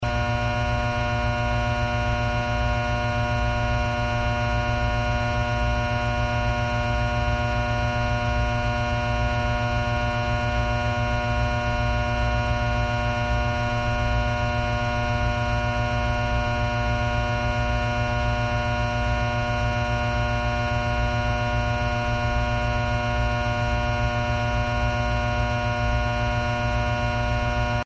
Звук гудения электротрансформатора